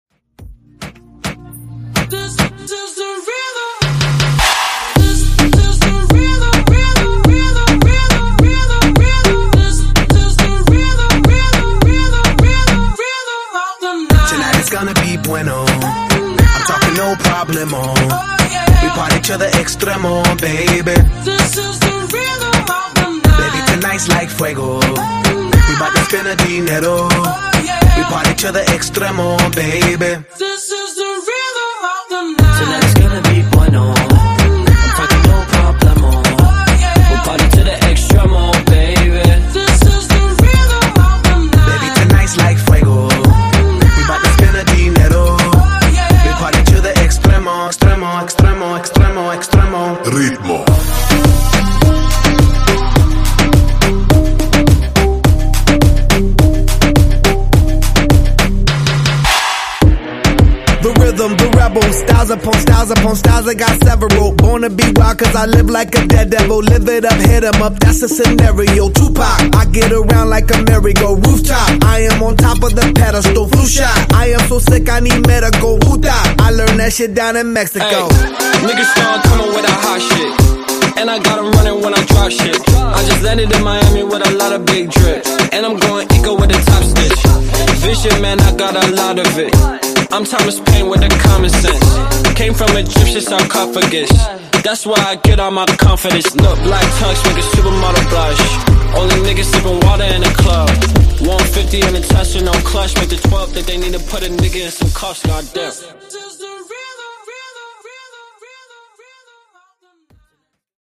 Genre: RE-DRUM Version: Dirty BPM: 85 Time